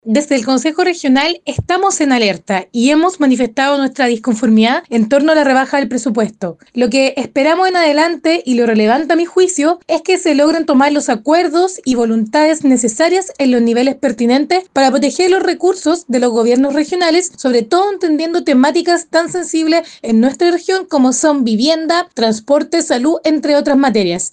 En ese contexto, María Elena Rubilar, consejera del Frente Amplio de la provincia de Marga Marga, manifestó su disconformidad frente a esta problemática que afectaría a diversas áreas a nivel regional.